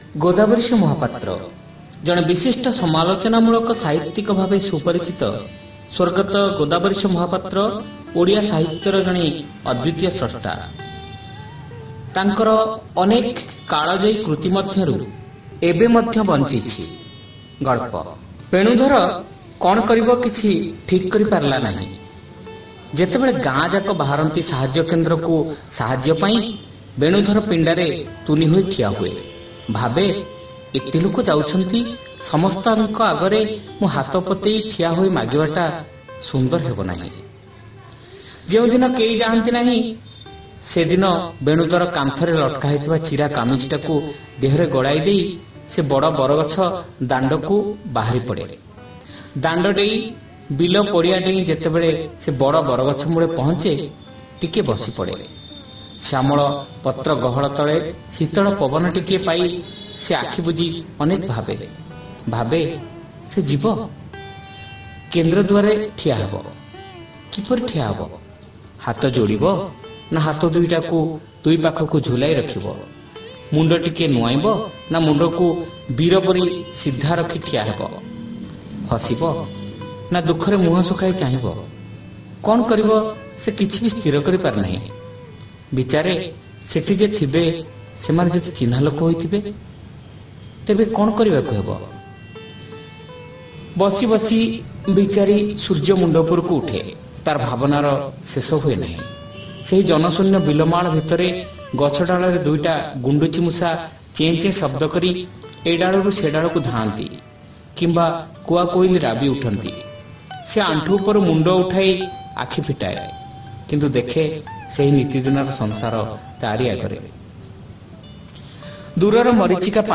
Audio Story : Ebe Madhya Banchichhi